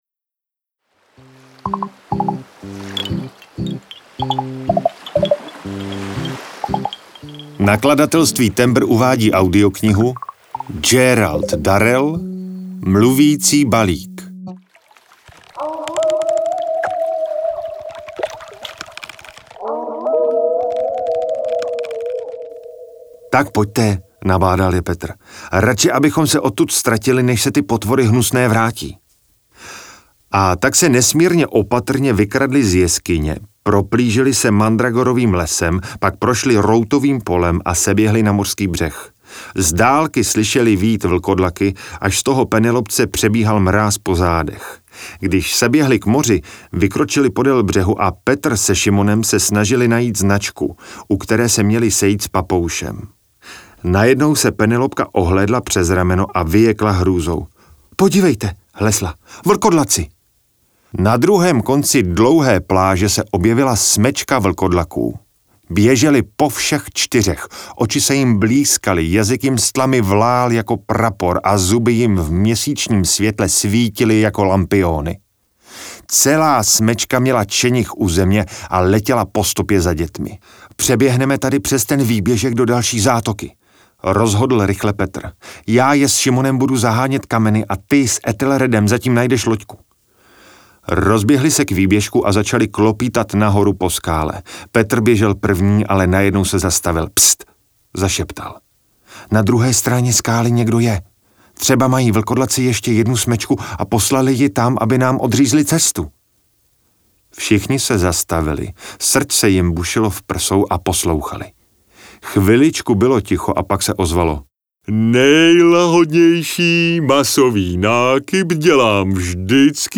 Audiokniha
Natočeno ve studiu STUDIO BEEP ¬a.¬s.